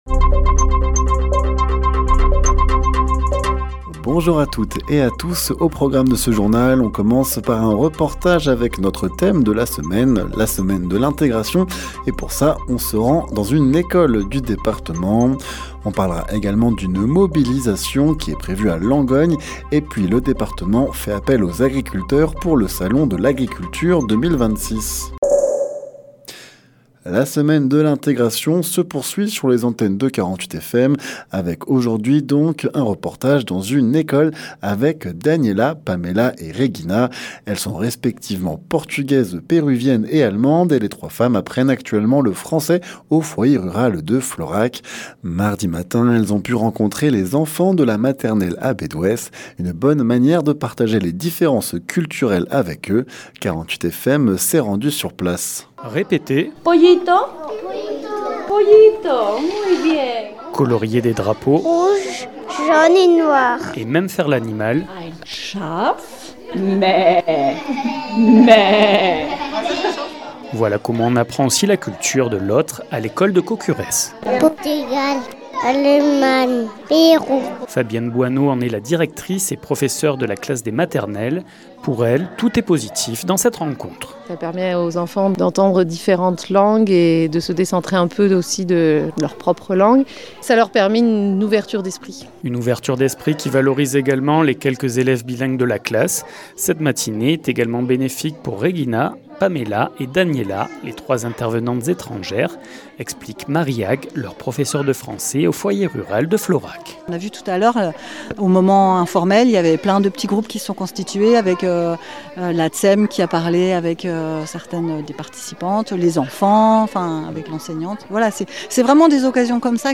Le journal sur 48FM présenté par